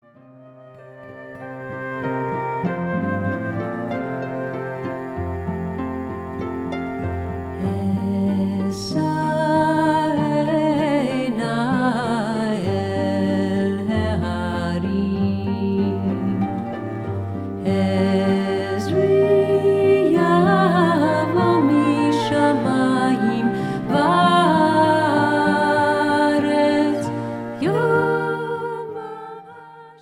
A combination of cantorial and Middle-Eastern motifs.